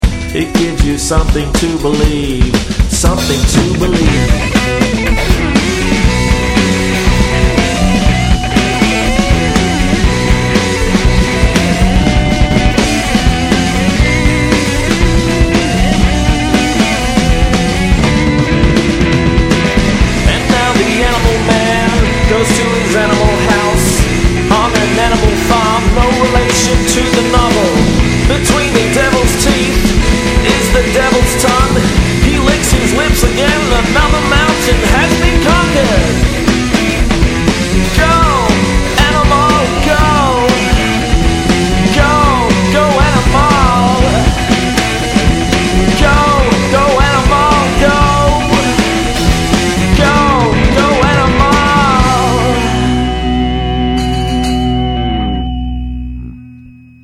446k MP3 (57 secs, mono)